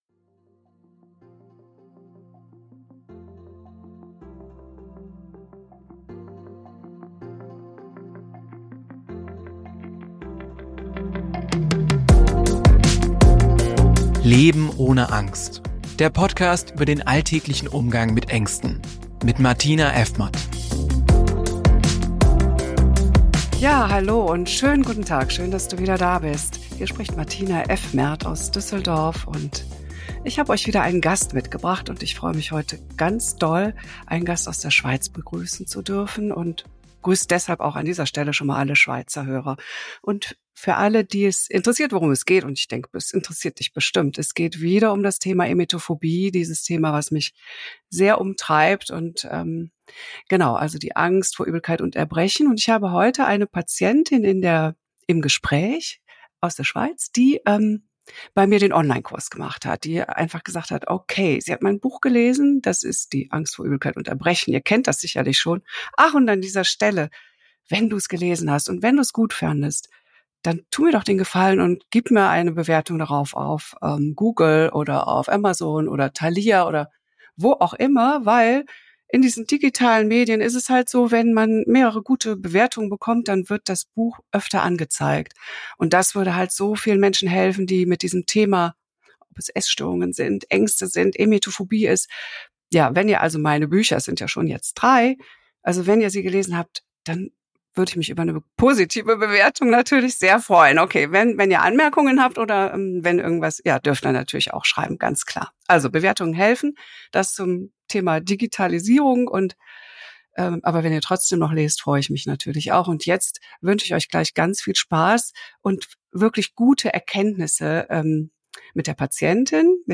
Wege aus der Emetophobie mit Online-Kurs Emunis – Gespräch mit einer Teilnehmerin ~ Leben ohne Angst